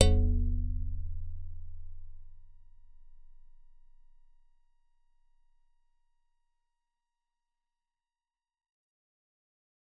G_Musicbox-C0-f.wav